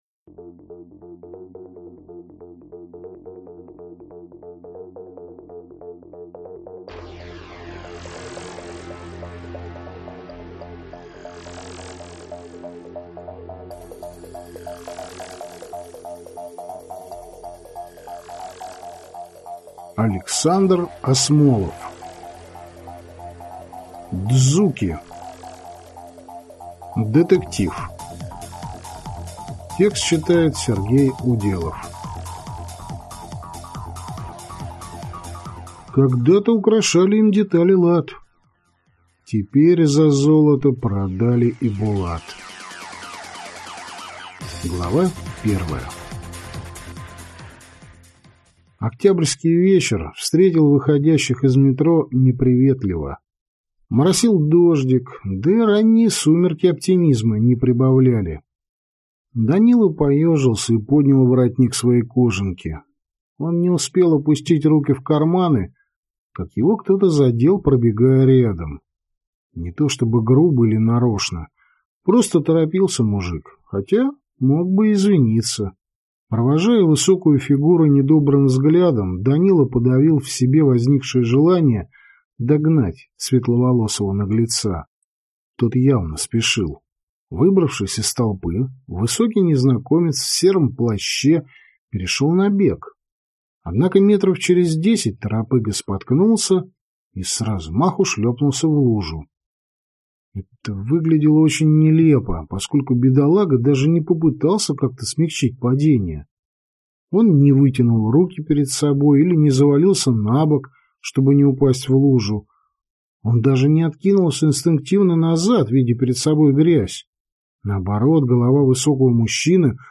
Aудиокнига Дзуки